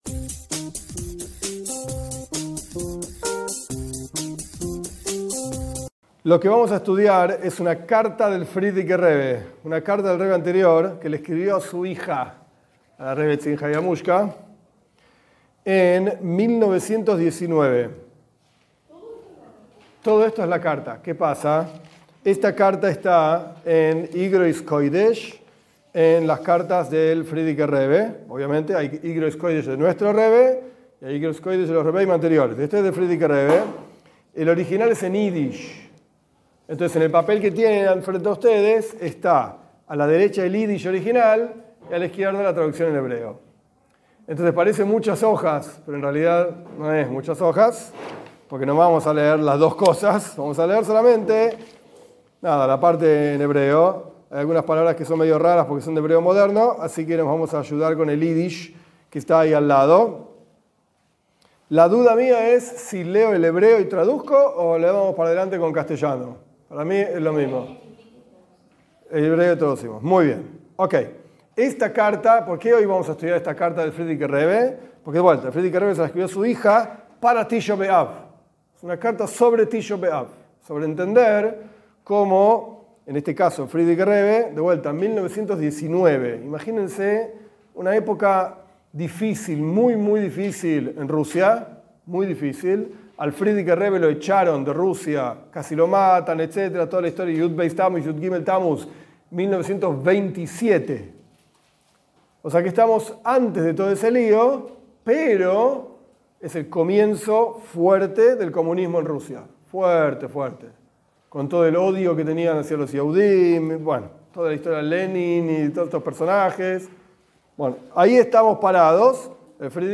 Esta clase es la lectura y explicación de una carta del Rebe anterior, Rabí Iosef Itzjak Schneerson, de bendita memoria, a su hija Jaia Mushka, quien sería la esposa del Rebe. En la carta el Rebe le relata la historia de este triste día y da aliento para no caer en los mismos errores que llevaron a vivir un día así.